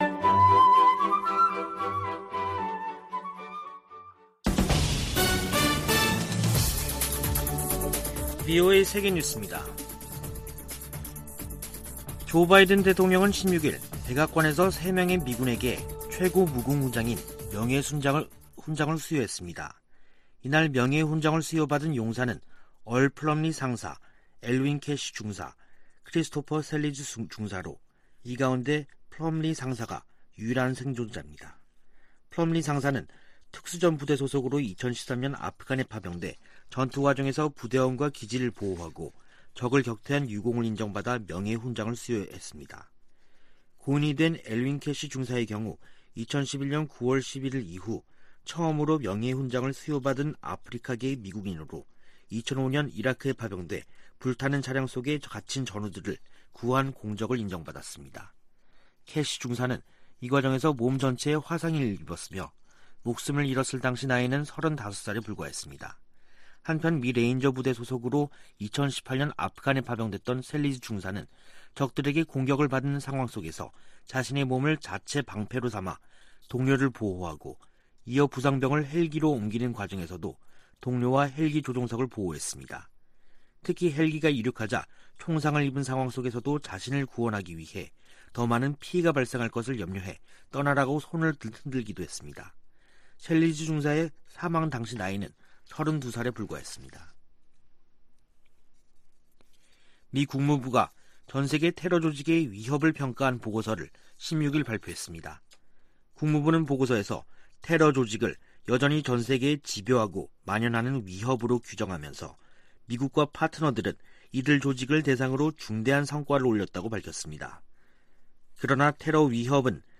VOA 한국어 간판 뉴스 프로그램 '뉴스 투데이', 2021년 12월 17일 2부 방송입니다. 미 국무부는 북한이 반복적으로 국제 테러 행위를 지원하고 있다고 보고서에서 지적했습니다. 미국은 북한과 대화와 외교를 통한 한반도의 항구적인 평화를 위해 노력하고 있다고 국무부 부차관보가 밝혔습니다. 미 국무부가 미한 동맹의 중요성을 거듭 확인하면서 중국과 관여하는 문제에도 협력을 강조했습니다.